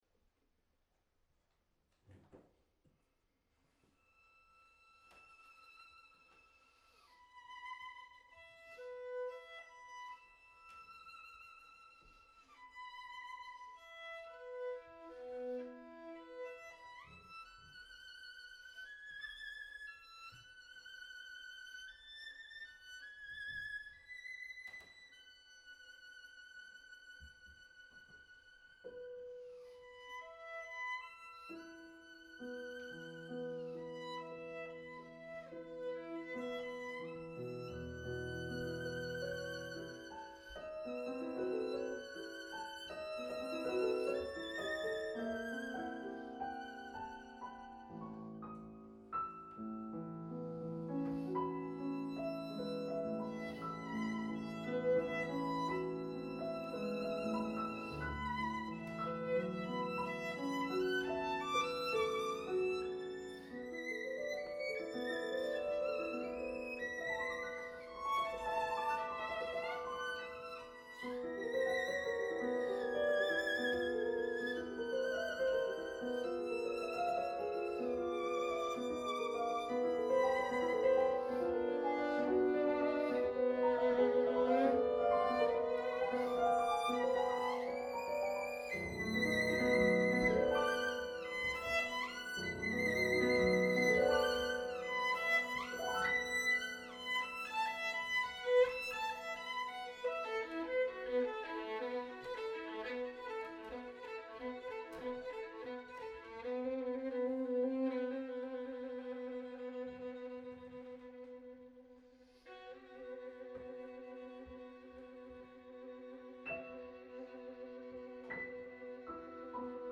Venue: Bantry Library
Instrumentation Category:Duo
violin
piano